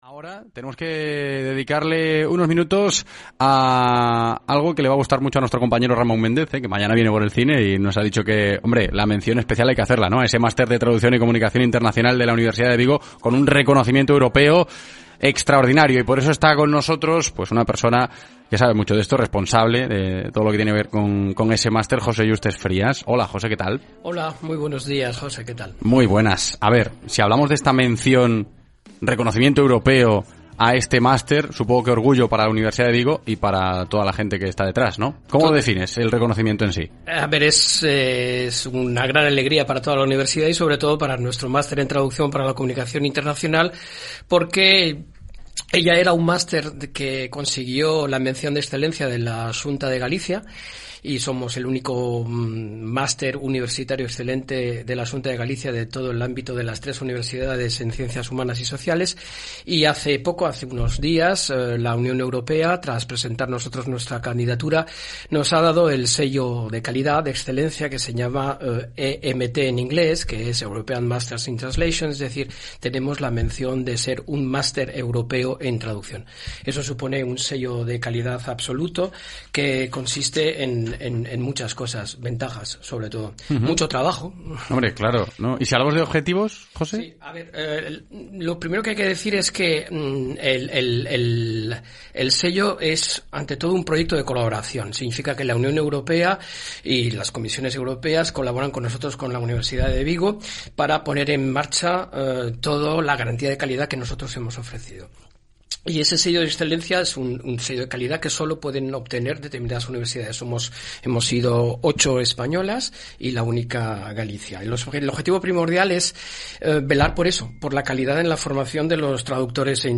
Entrevista radiofónica